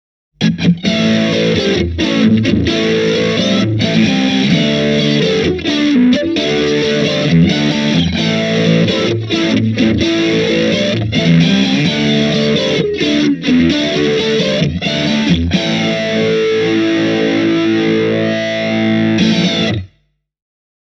The sounds on offer cover all bases, and the amp types and effects all sound great.
These four examples have been recorded with the same set-up are the Micro Cube GX-clips: